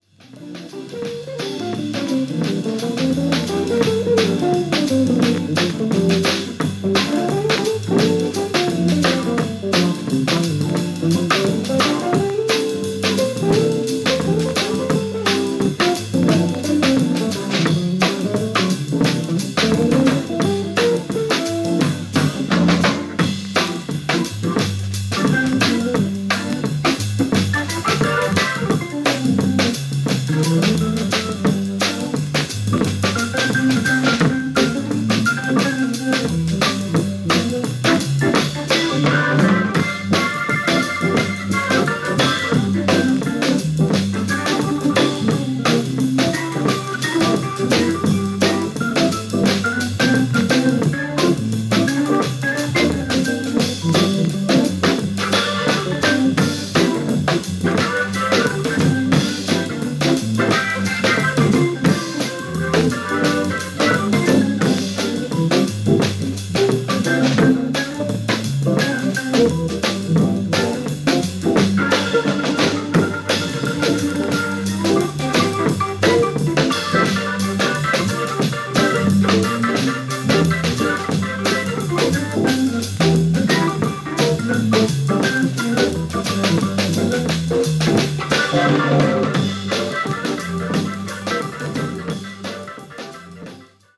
Baritone Saxophone
Bass
Drums, Gong
Piano
Tenor Saxophone